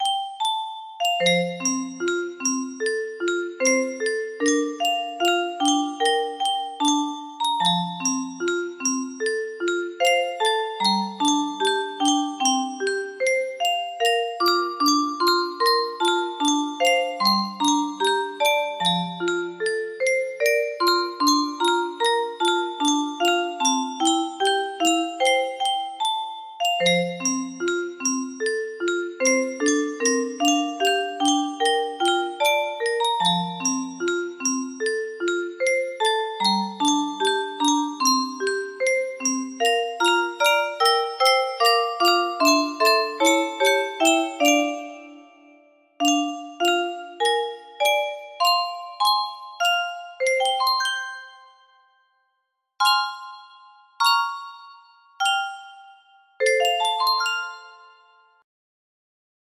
for Grand Illusion 30 (F scale) Music Boxes